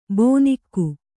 ♪ bōnikfku